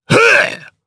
Phillop-Vox_Attack1_jp.wav